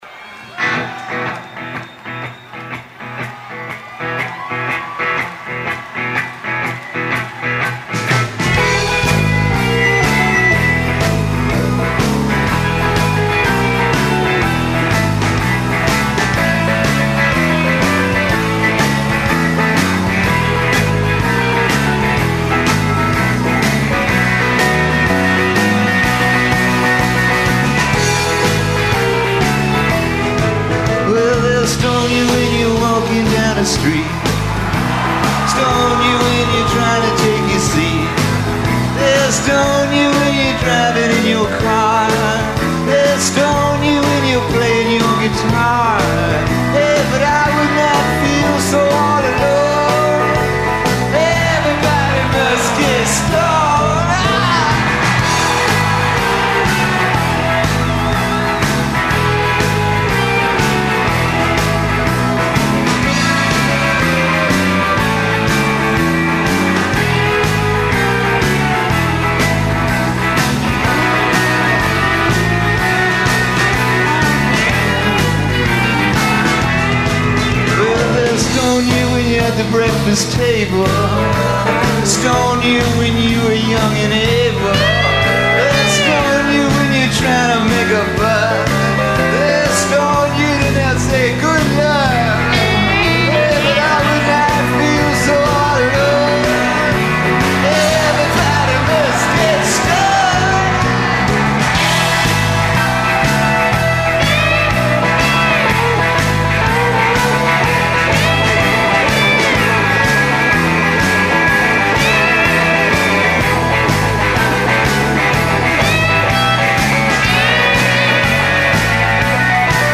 at a homecoming Gainesville FL concert in November 1993
performing live